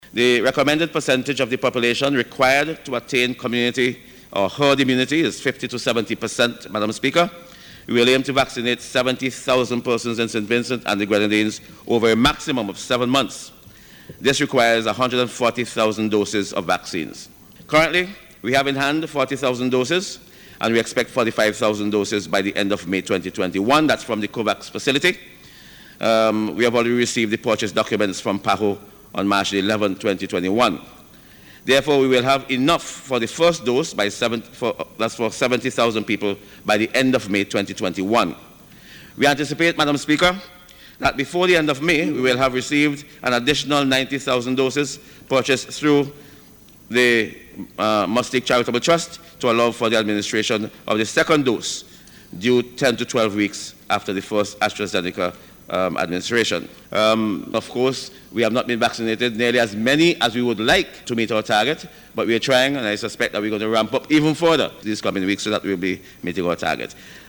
This is according to Minister of Health, Wellness and the Environment, St. Clair Prince.
He was responding to a question from the Opposition about the Government’s plan for the vaccination of the population of St. Vincent and the Grenadines against the COVID-19 Virus.